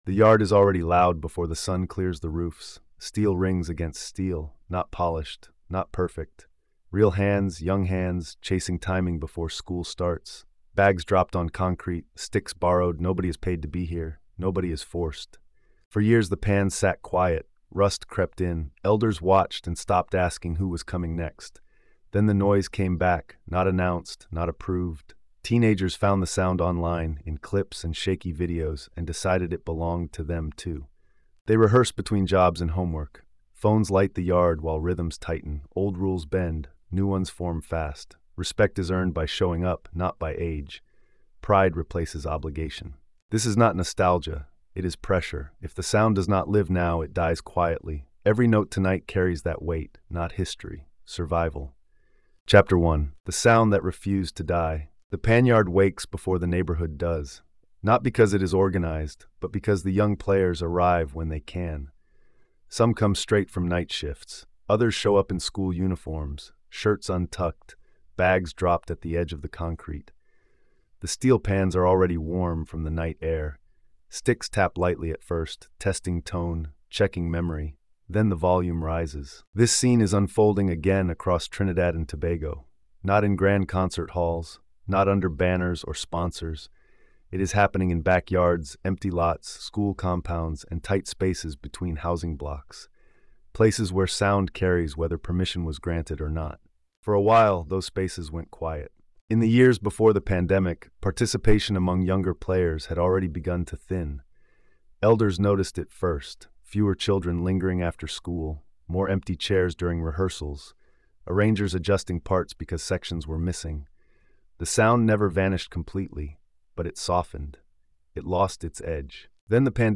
Steelpan Renaissance: A New Generation Revives Old Trinidad and Tobago Soul is a gritty cultural documentary that follows the quiet but determined return of steelpan culture among young people in Trinidad and Tobago.